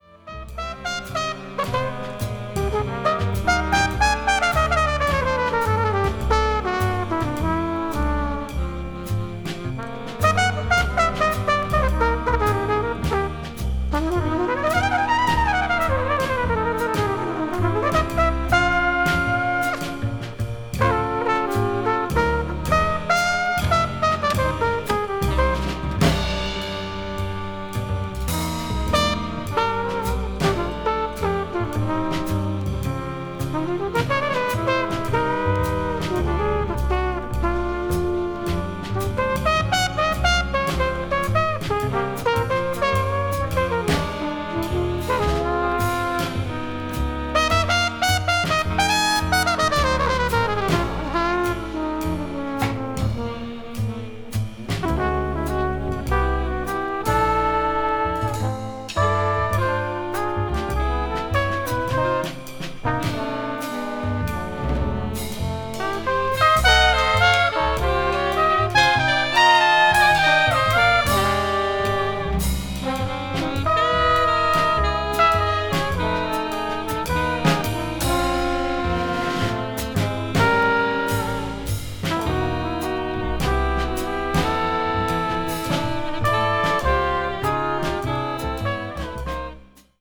avant-jazz   contemporary jazz   ethnic jazz   free jazz